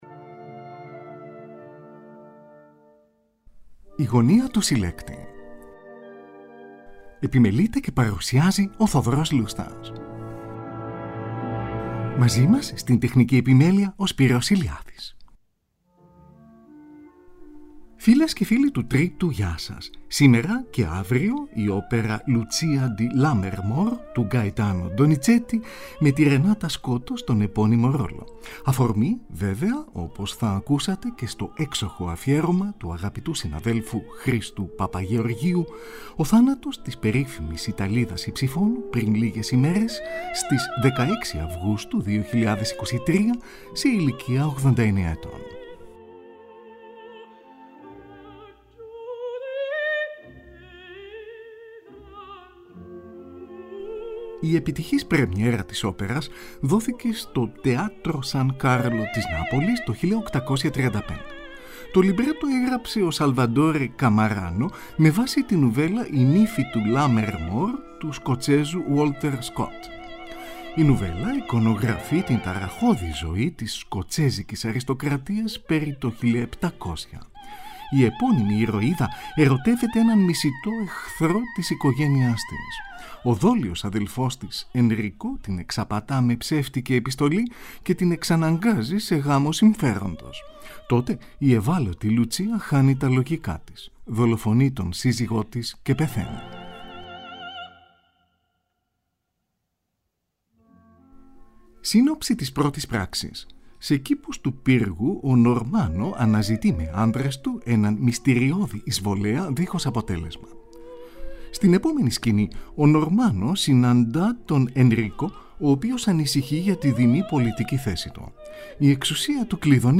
Lucia, η υψίφωνος Renata Scotto.
Εdgardo di Ravenswood, ερωτευμένος με τη Lucia, o τενόρος Luciano Pavarotti.
Tη Χορωδία και την Ορχήστρα της RAI του Torino διευθύνει ο Francesco Molinari-Pradelli. Zωντανή ραδιοφωνική ηχογράφηση, στις 30 Ιουνίου 1967.